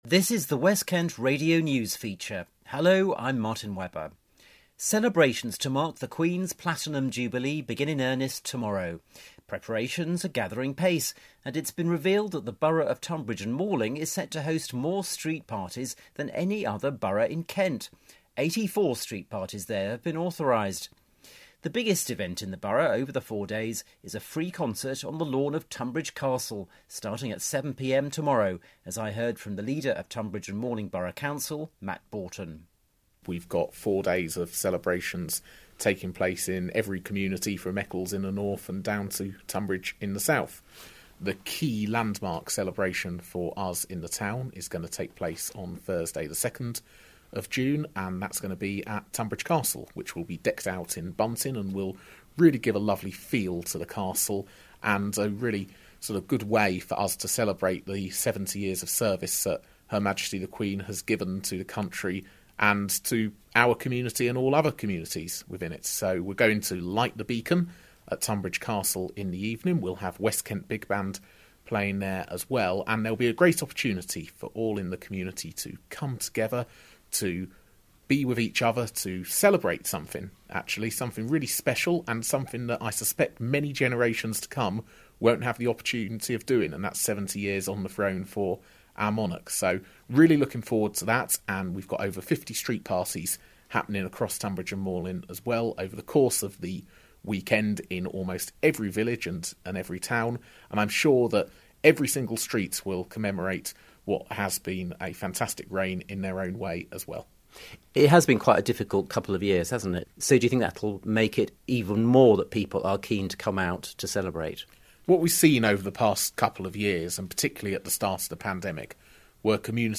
the leader of the council Matt Boughton has been explaining